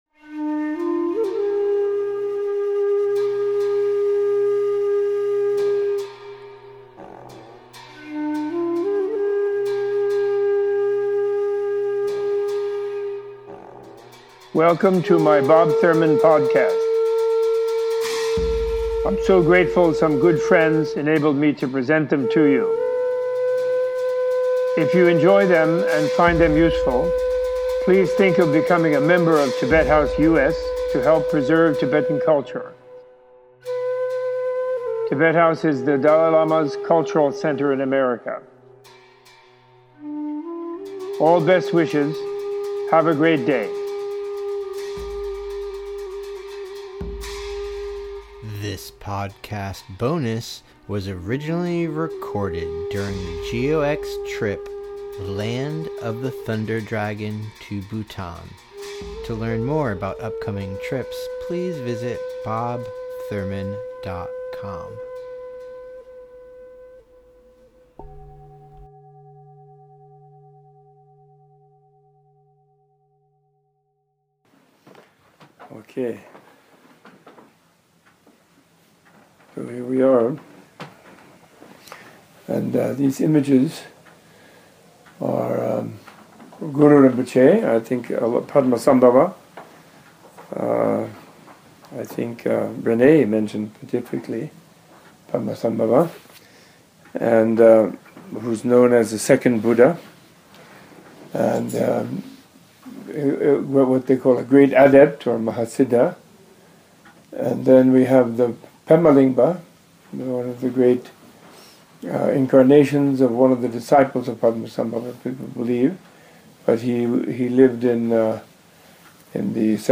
Robert A.F. Thurman in this archive discusses how pilgrimage combined with study of Buddhist Philosophy can help anyone encounter the bliss at the core of his mind transformation practices and leads a guided meditation. Using the colorful depictions of the Padmasambhava, Pema Lingpa and Zhabdrung Ngawang Namgyel Professor Thurman explains the role of meditation deities in Tibetan Buddhism, the different understandings of Iconography and of Idolatry that Western and Eastern viewers hold.